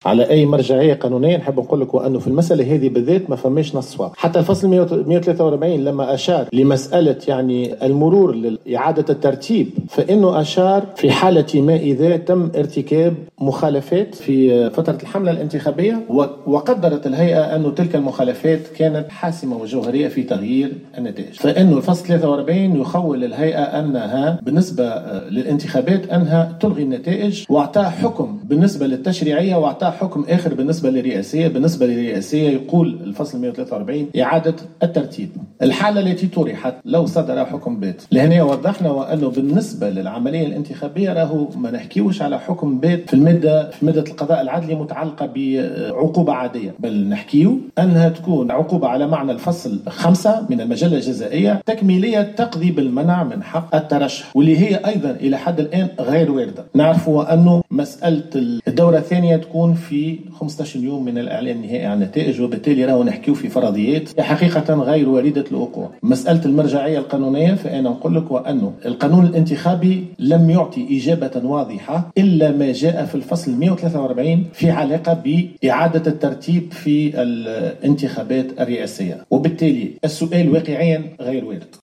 أكد رئيس الهيئة العليا المستقلة للانتخابات نبيل بفّون في ندوة صحفية مساء اليوم أن القانون الانتخابي لم يعط إجابة واضحة بخصوص الوضعية القانونية للمترشح نبيل القروي التي تشير معطيات اولية إلى تصدره نتائج الانتخابات مع قيس سعيّد. وأضاف أنه ليس هناك اجابة لمثل هذه الحالات باستثناء ما جاء في الفصل 143 في علاقة بإعادة الترتيب في الانتخابات الرئاسيّة.